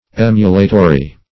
Search Result for " emulatory" : The Collaborative International Dictionary of English v.0.48: Emulatory \Em"u*la*to*ry\, a. Pertaining to emulation; connected with rivalry.